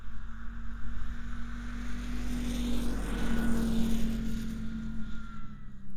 Internal Combustion Subjective Noise Event Audio File (WAV)